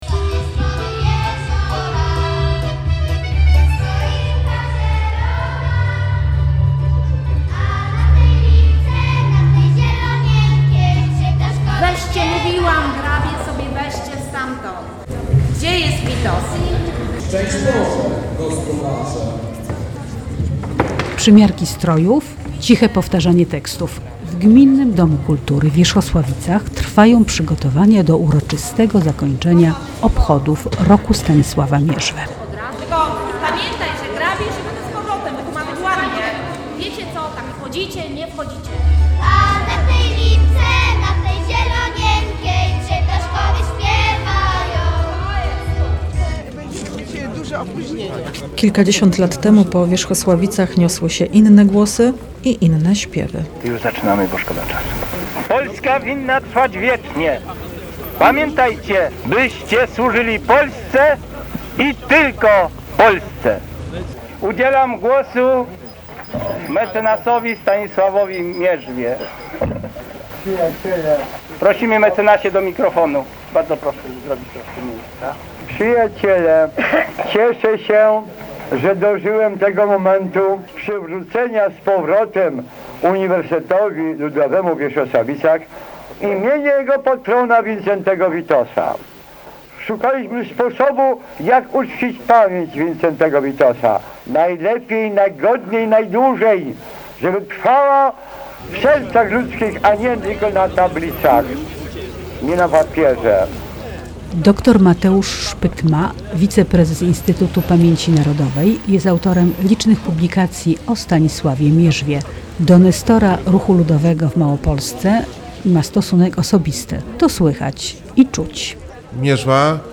Reportaż w Radiu Kraków